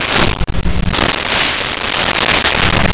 Whip Crack 02
Whip Crack 02.wav